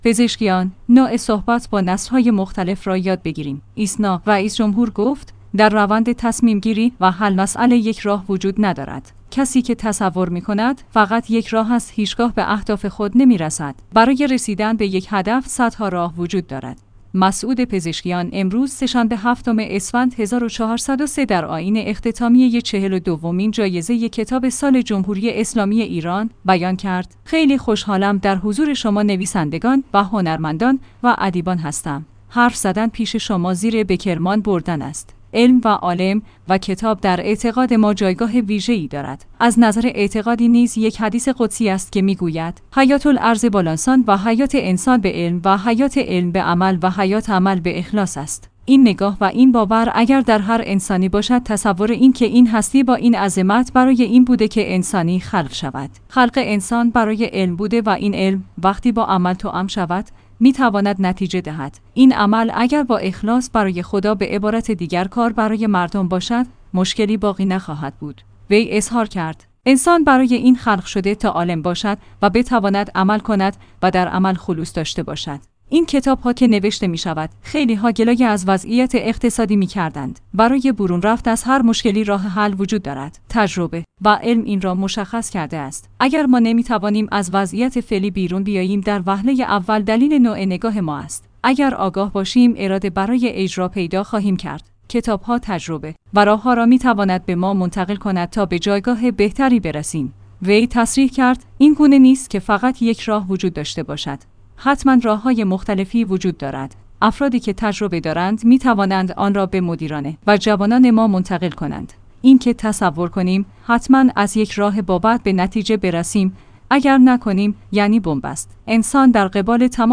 مسعود پزشکیان امروز سه شنبه هفتم اسفند ۱۴۰۳_ در آئین اختتامیه چهل و دومین جایزه کتاب سال جمهوری اسلامی ایران، بیان کرد: خیل